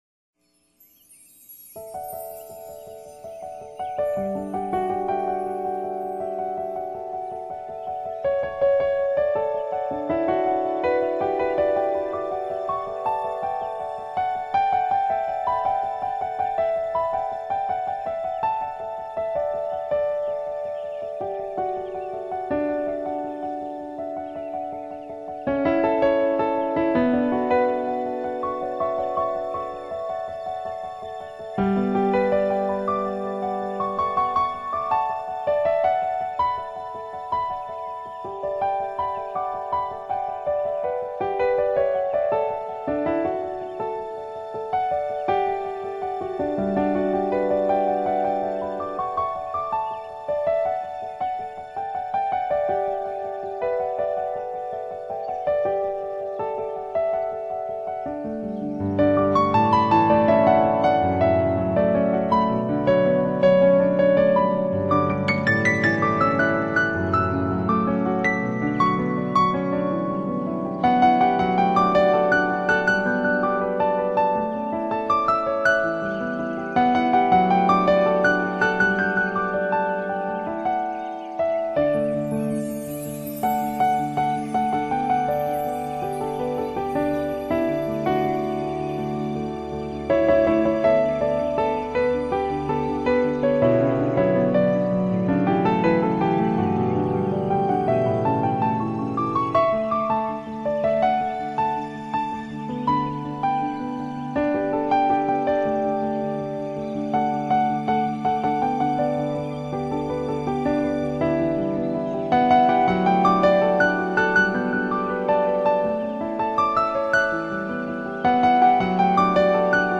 钢琴的演奏低缓而涌动，清脆而强硬。小提琴高亢而嘹亮，牵引着旋律的丝线逐步高升。